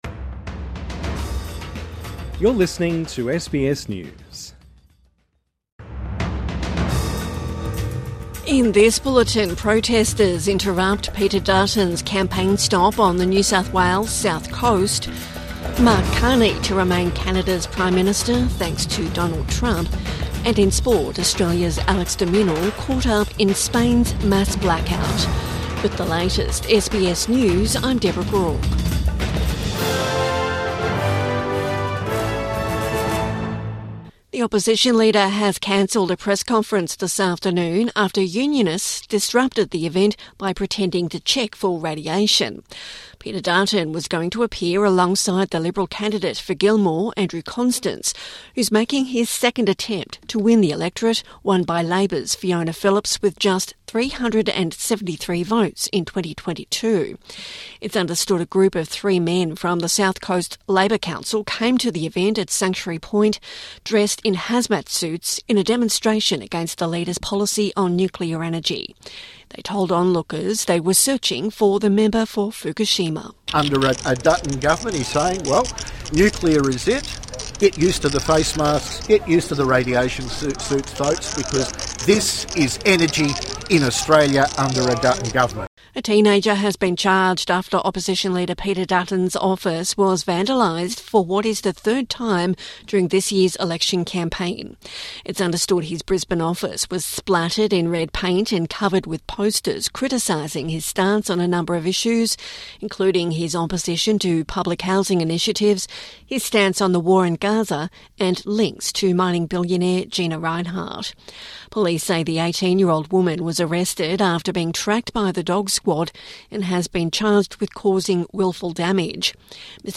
Protesters interrupt Dutton's NSW south coast campaign stop | Evening News Bulletin 29 April 2025